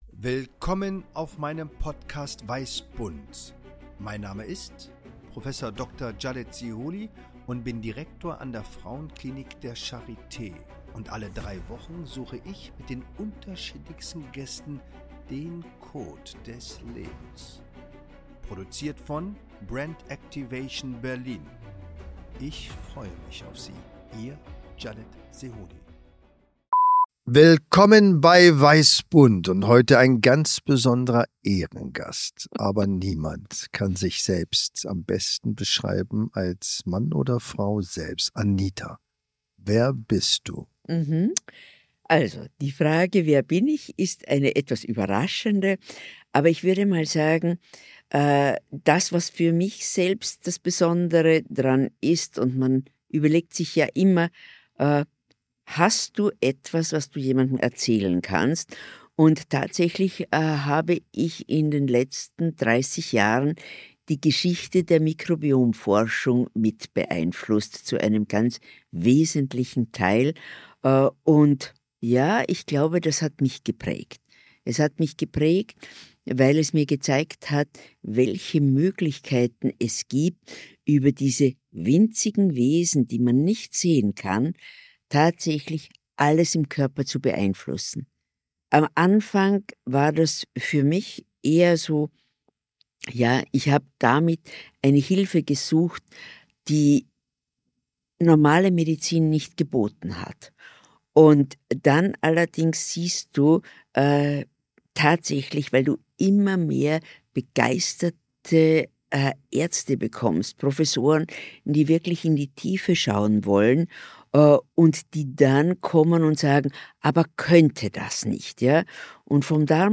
Spontan, intuitiv, ohne Skript, Improvisation pur!